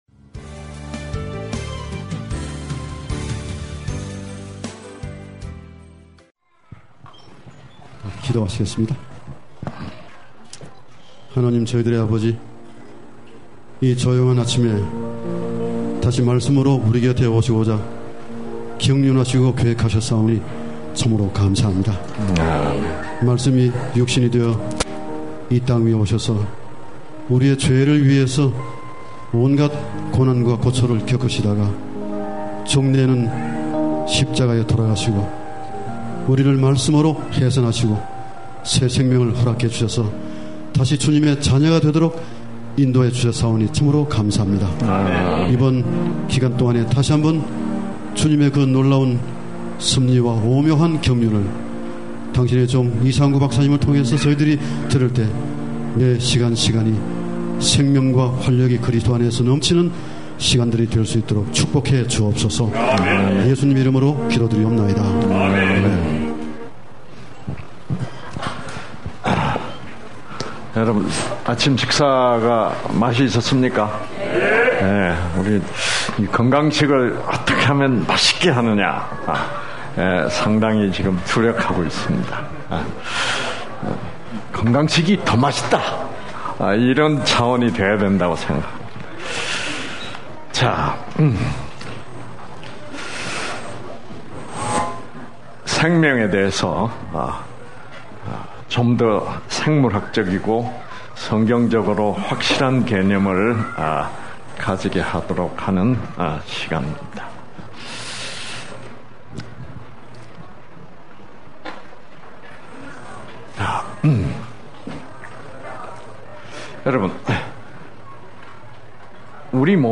날짜 번호 방송시각 강사 강의제목(동영상) MP3(음성) 8.14 1 오후 7:30 이상구 박사 강의 01 생명이라는 것이 존재하는가? 다운로드 8.15 2, 3 오전 8:30 이상구 박사 강의 02 영적 에너지는 물리적 에너지를 지배한다 강의 03 관찰자는 누구인가?